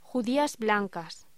Locución: Judías blancas
voz